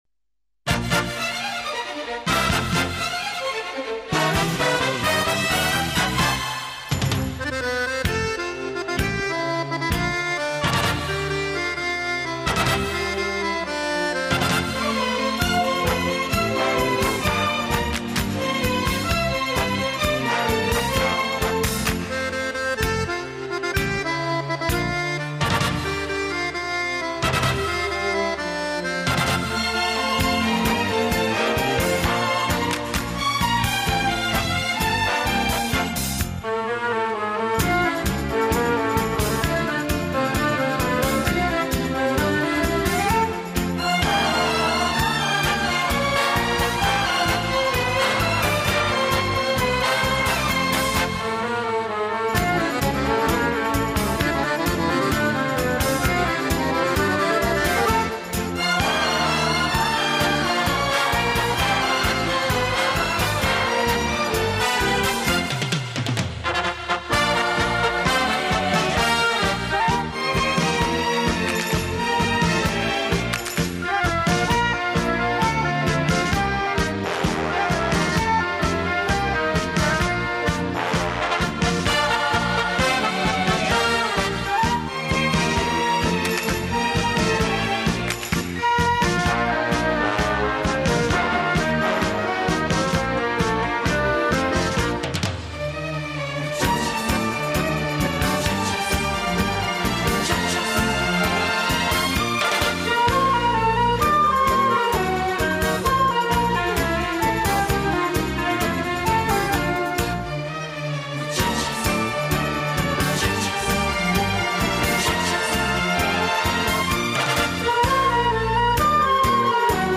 Genre: Easy Listening,Instrumental